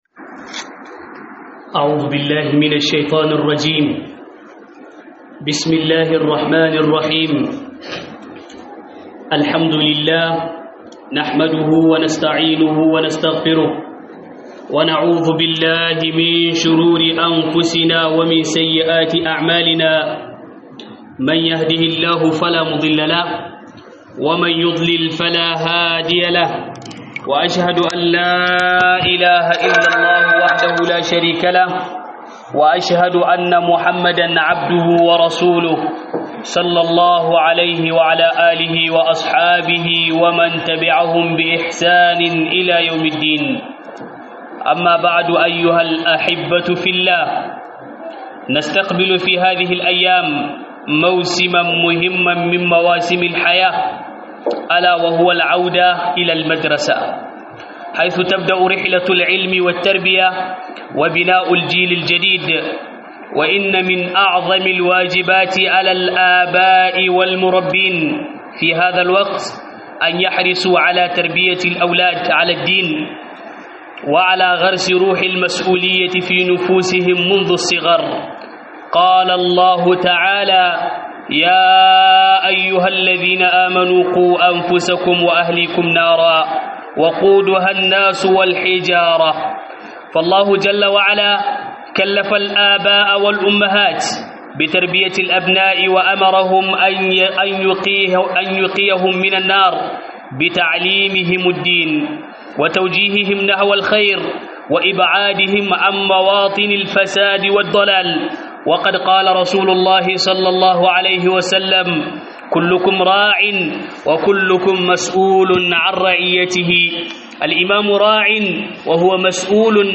Huɗubar juma'a Rentrée scolaire